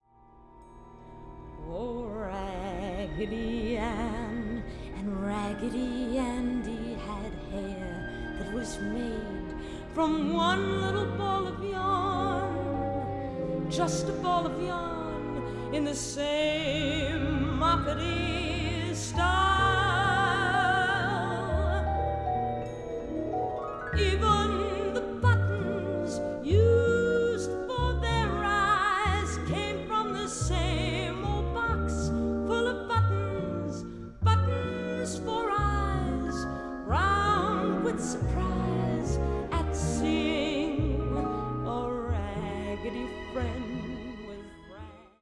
ハッピーでスウィートなウィンター・ソングが詰まっています。